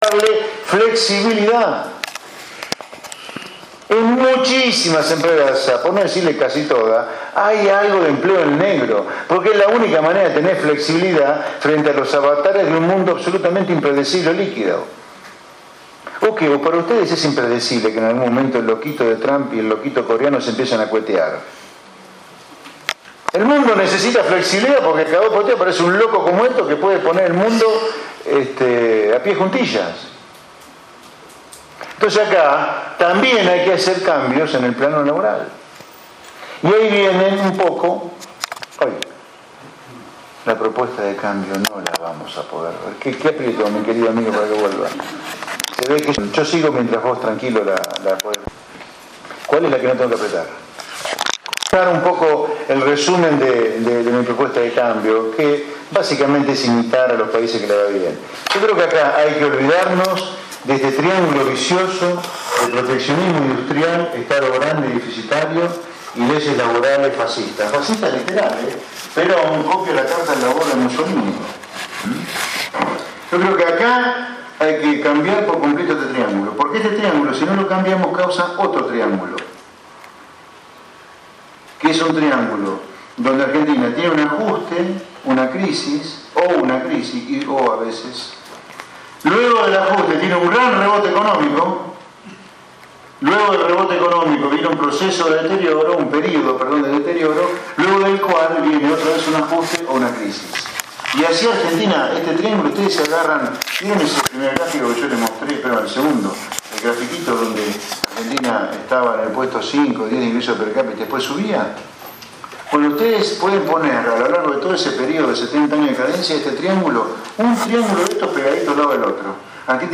José Luis Espert Paso por La Federación de Comercio e Industria de San Nicolás
Dejamos algunos pasajes de su  singular y autoritaria oratoria de este  profesional que desde su púlpito dejó callados a todos.